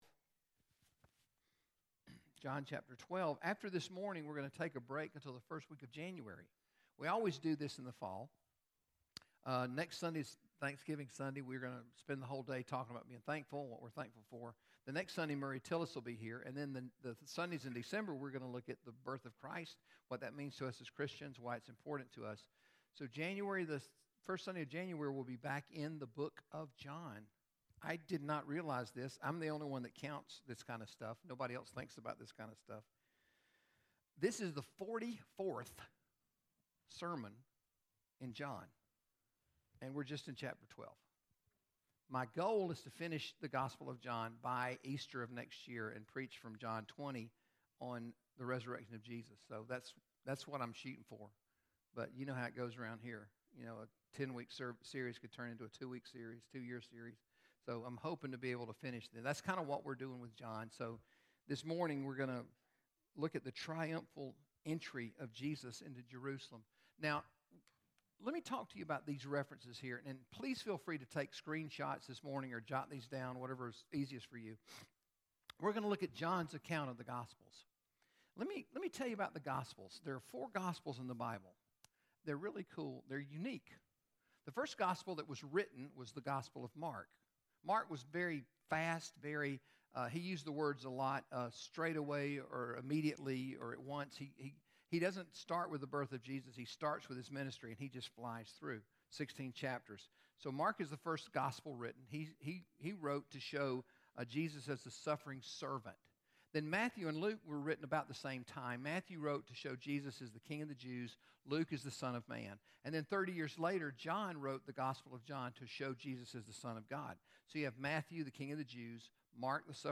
GCC Sermons | Gwinnett Community Church Sermons